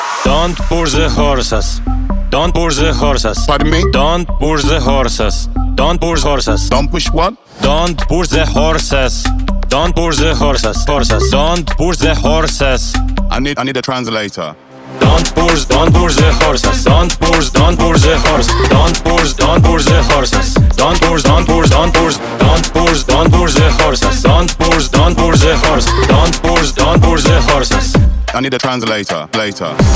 Dance
Жанр: Танцевальные / Украинские